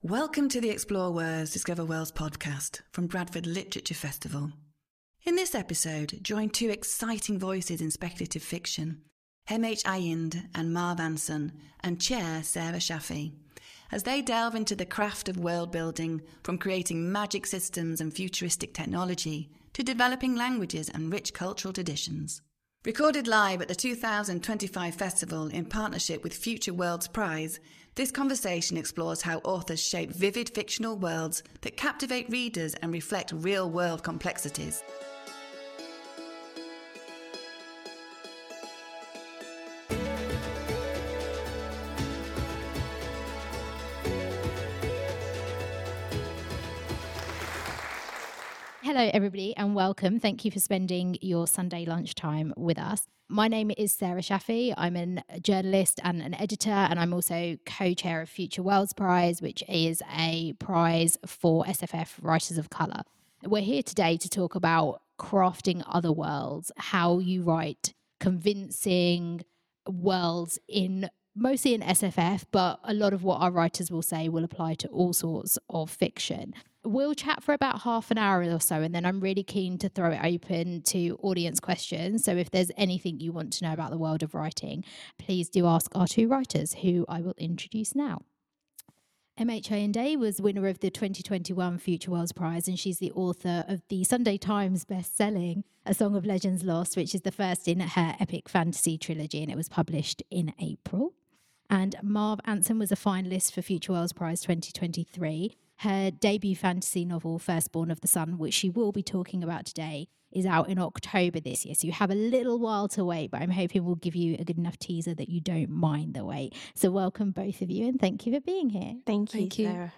this conversation explores how authors shape vivid fictional worlds that captivate readers and reflect real-world complexities.